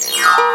HARPDNGD.WAV